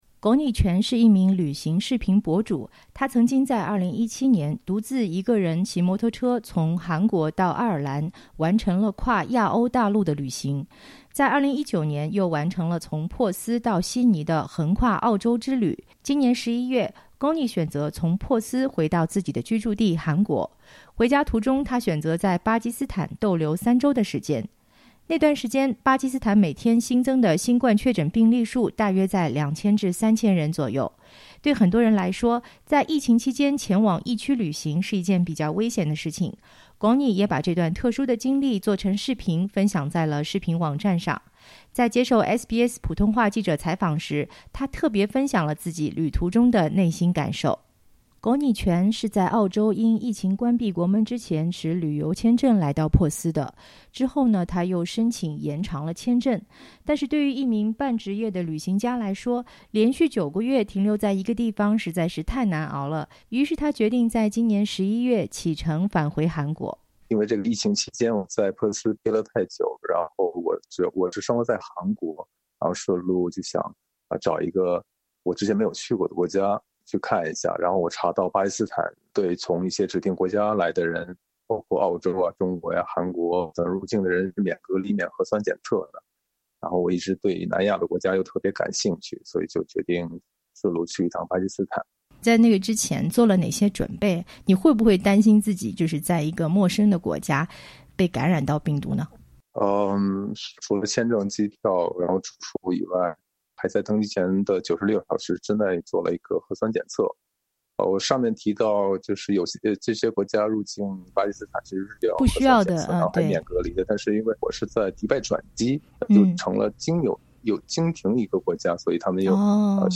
在接受SBS普通话记者采访时，他聊了自己在旅途中的内心感受。